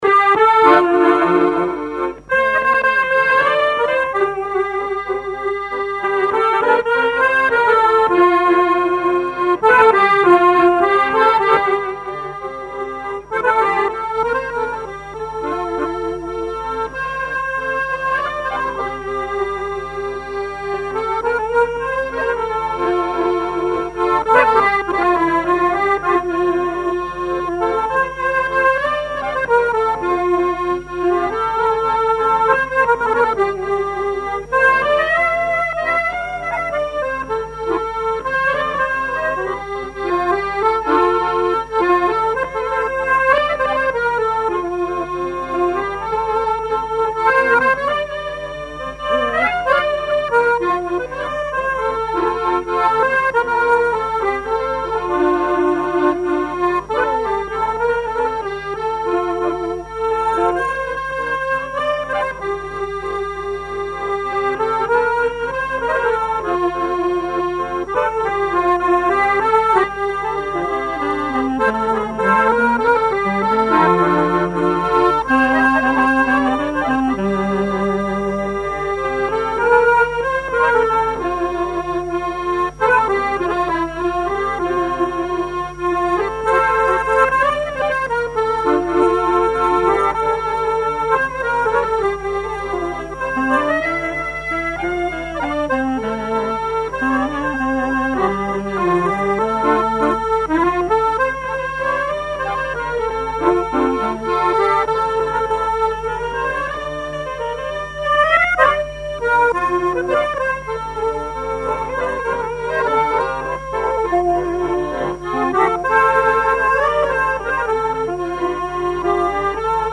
danse : valse
collectif de musiciens pour une animation à Sigournais
Pièce musicale inédite